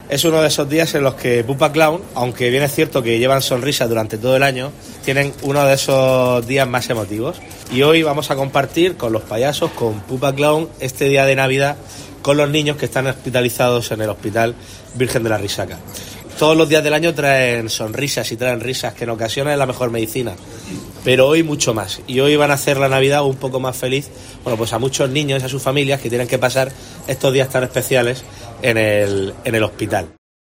Fernando López Miras, presidente de la Región de Murcia